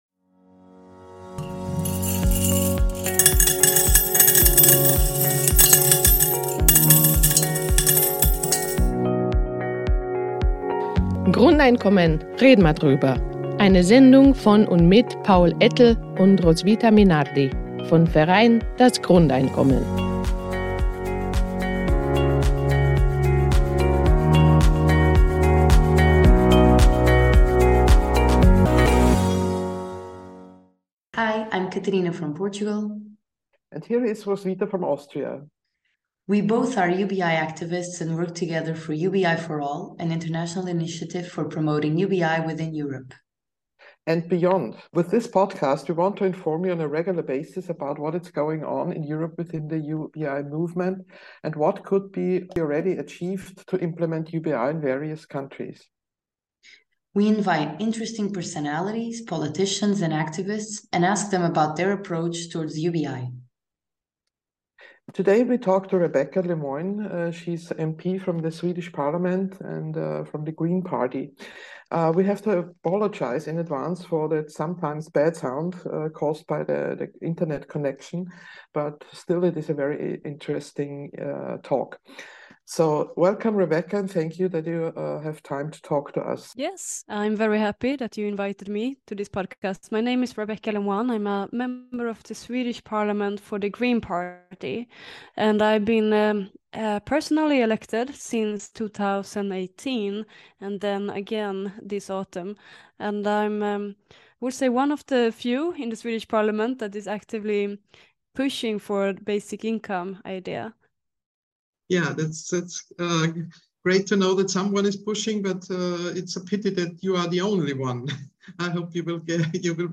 #80: Interview mit Rebecka Le Moine (member of the Swedish Parliament) ~ Grundeinkommen - Red'n ma drüber! Podcast